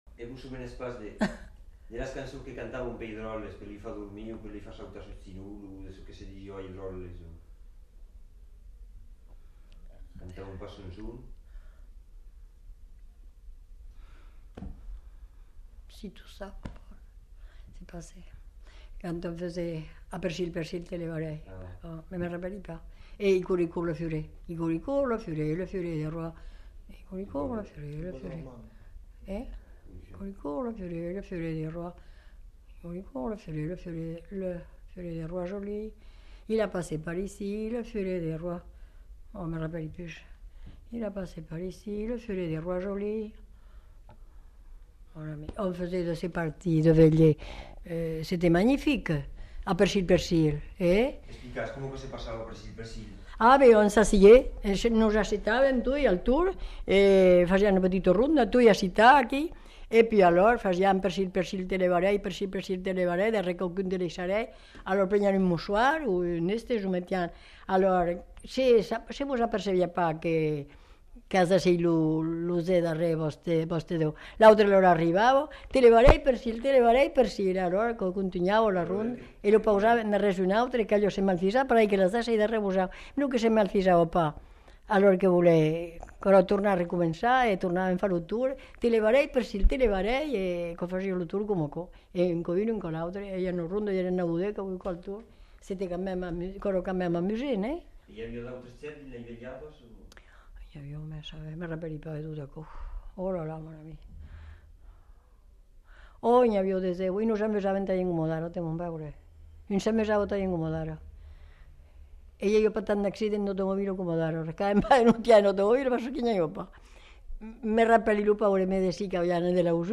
Aire culturelle : Haut-Agenais
Genre : témoignage thématique